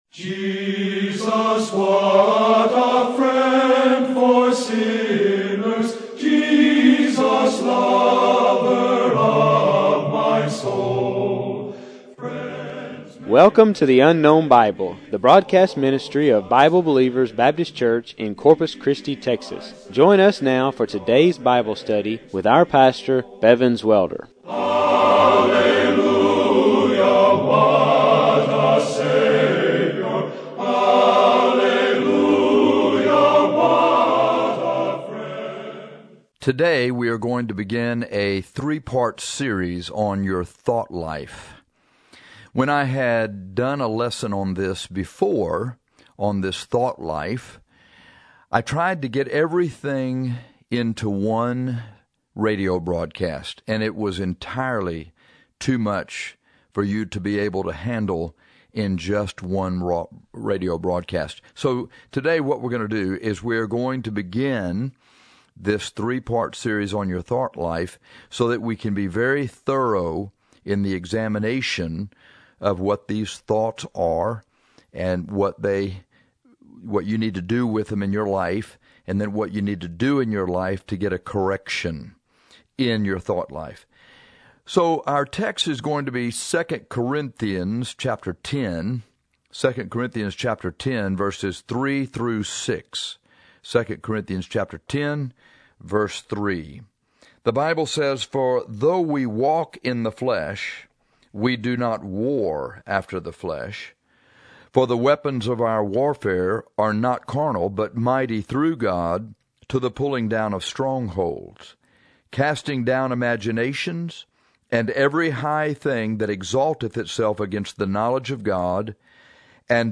This is the first of three broadcasts dealing with your thought life.